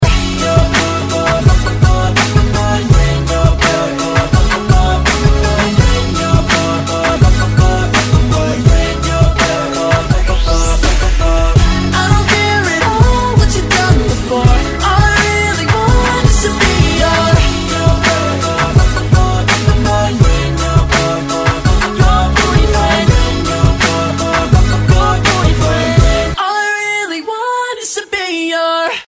Rap, RnB, Hip-Hop